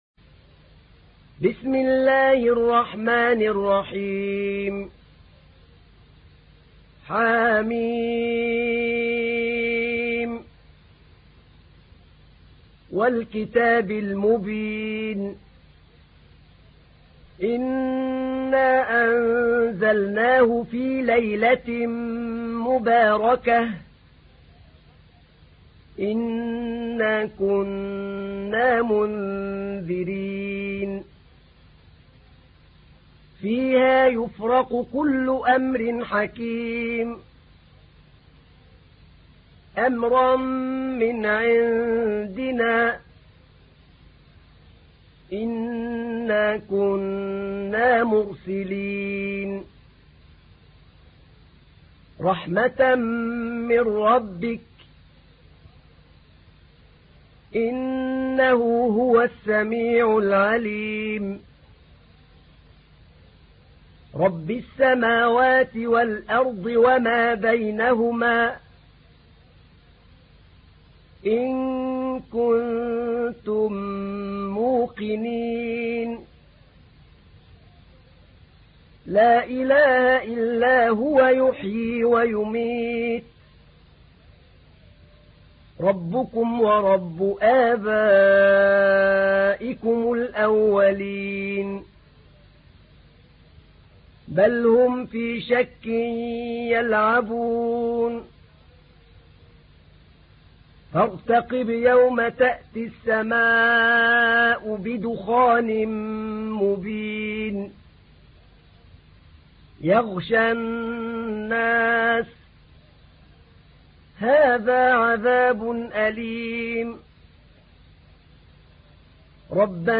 تحميل : 44. سورة الدخان / القارئ أحمد نعينع / القرآن الكريم / موقع يا حسين